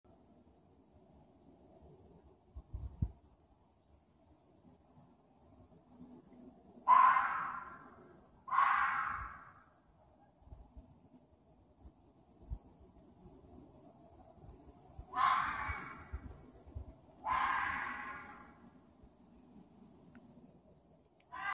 Red Fox Screeching Bouton sonore
Animal Sounds Soundboard2 195 views